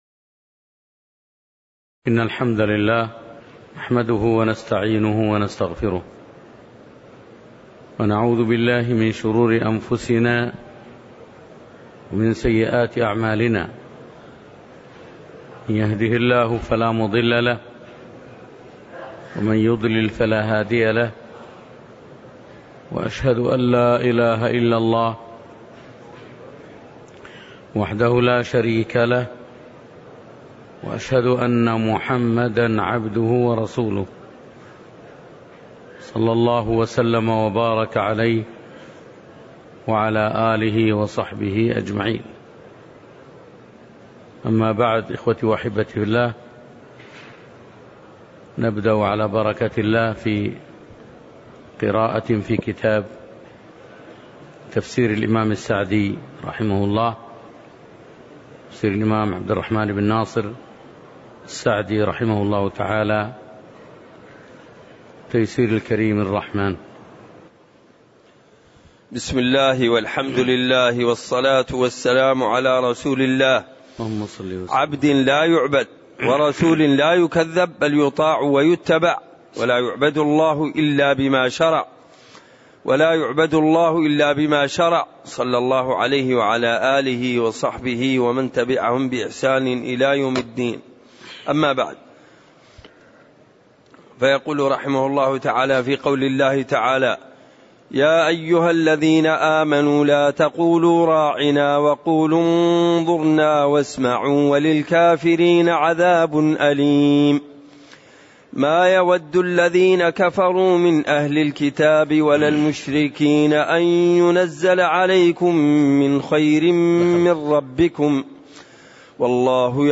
تاريخ النشر ٨ ربيع الثاني ١٤٣٨ هـ المكان: المسجد النبوي الشيخ